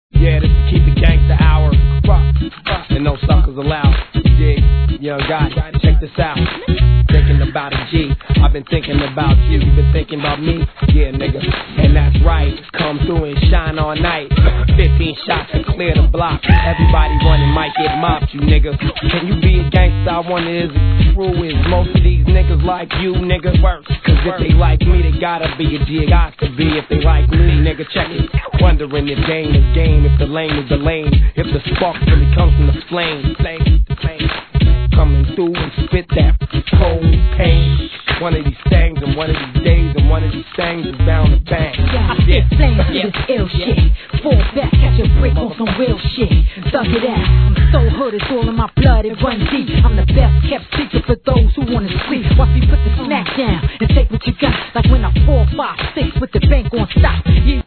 G-RAP/WEST COAST/SOUTH
2002年、マイナーなフィメール・グループG!